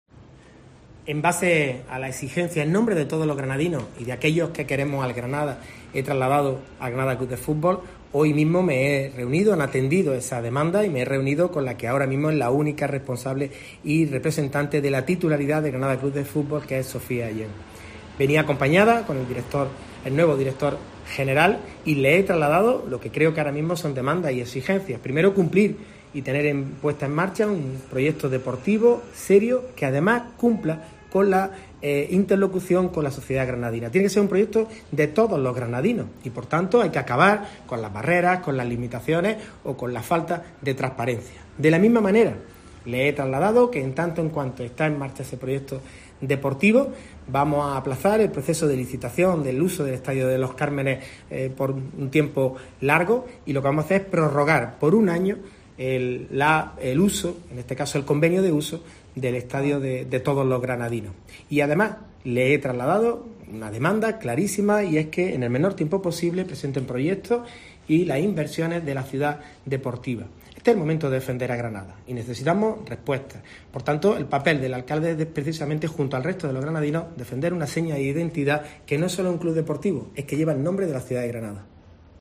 Las declaraciones del Alcalde las puede escuchar en el audio que acompaña esta información.